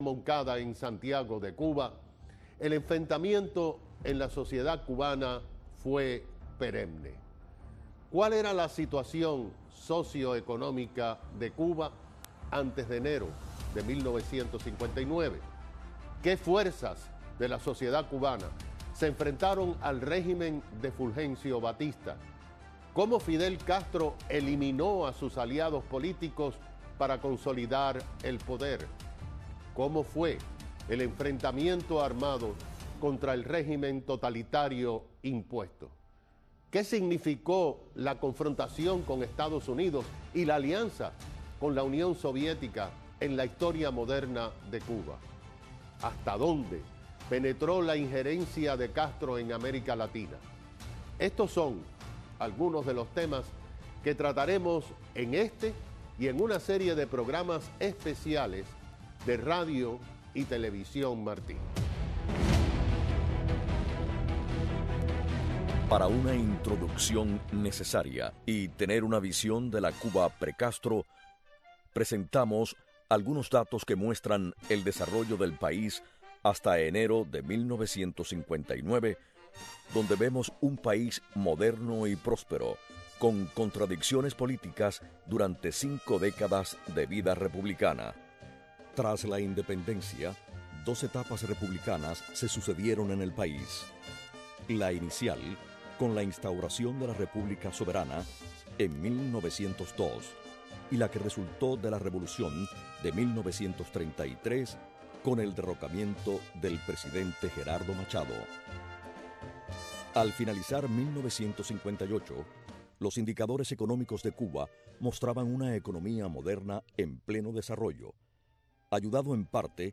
Presentador Tomas P. Regalado